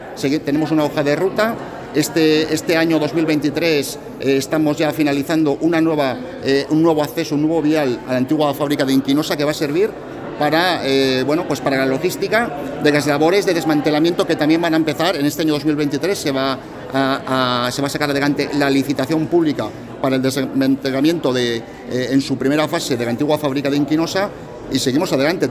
Carlos Gamarra, director general de Cambio Climático y Educación Ambiental: Desde 2014 no se ha registrado ninguna analítica positiva por HCH